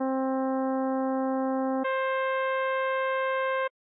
c1c2.ogg